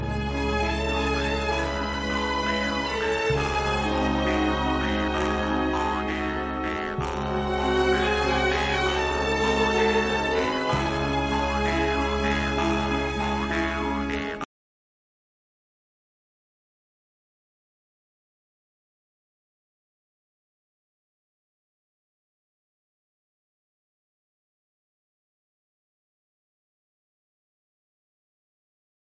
il coro siciliano è ripreso nella sua realtà antica
un’armonizzazione più moderna